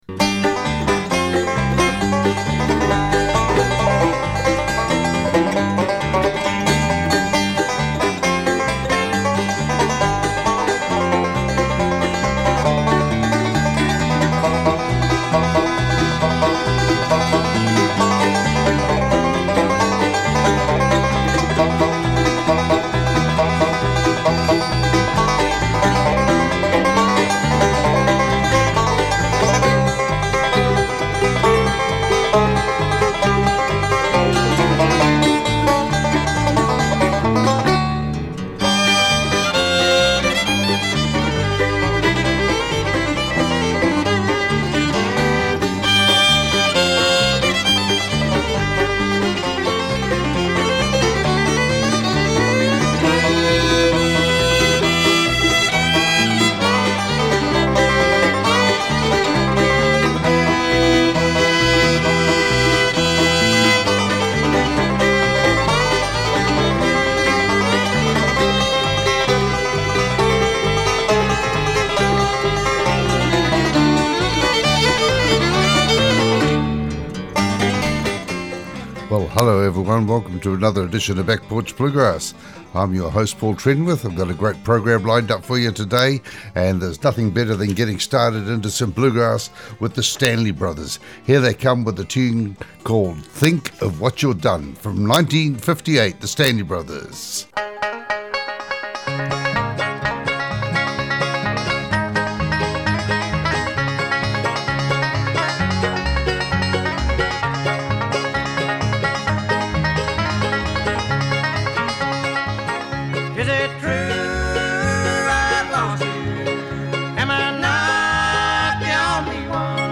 Back Porch Bluegrass Show